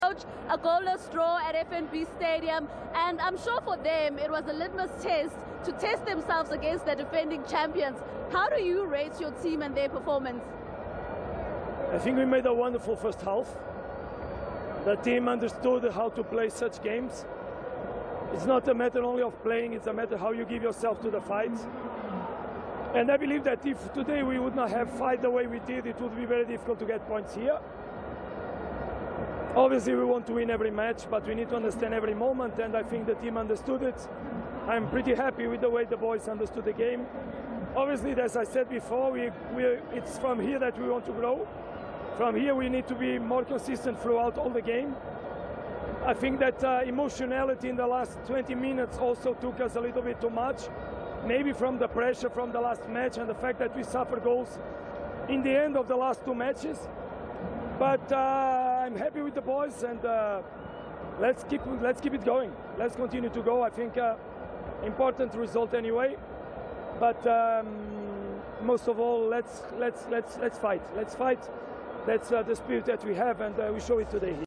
Mamelodi Sundowns coach Miguel Cardoso following their match against Kaizer Chiefs!